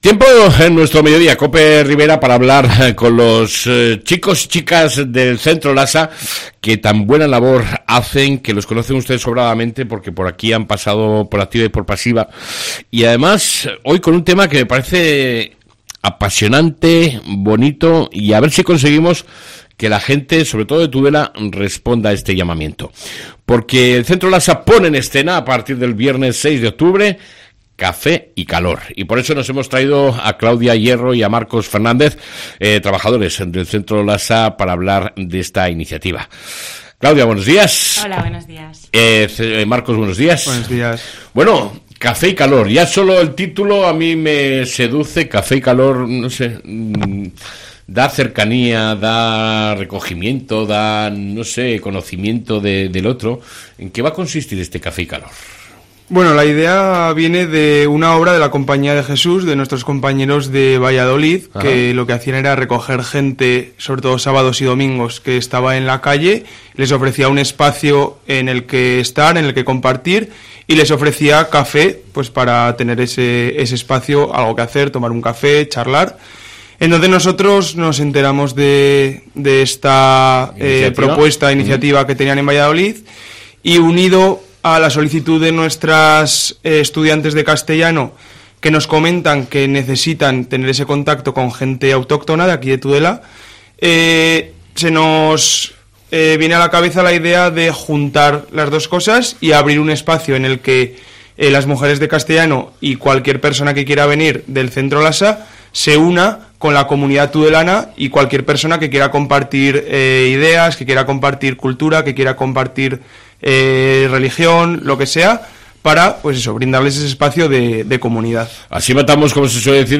ENTREVISTA CON EL CENTRO LASA